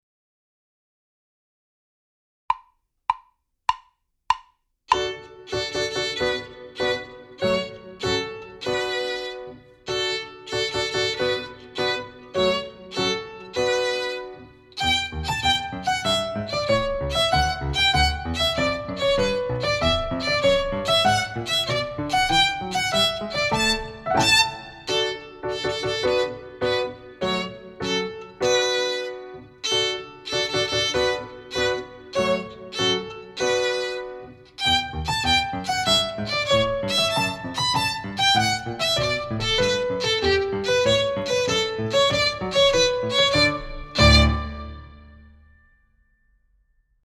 Set in the key of D it is all in first position.